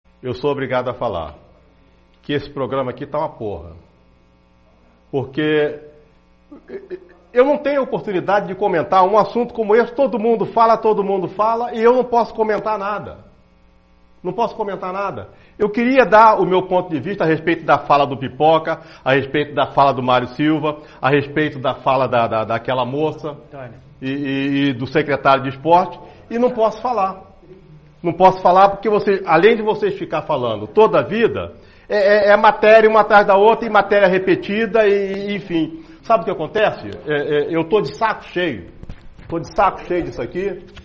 comentaristaesportivoabandonaprogramaaovivoringtone1_3zL9wpp.mp3